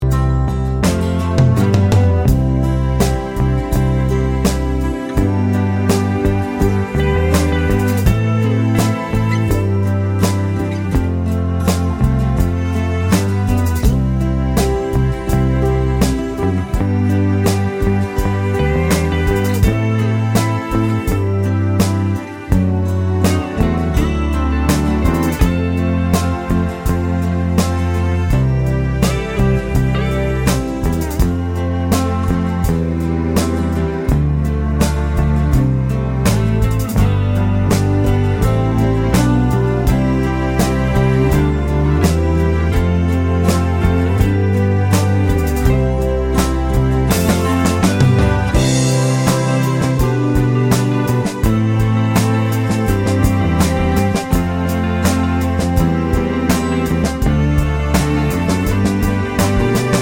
No Vocals At All Pop (1950s) 2:14 Buy £1.50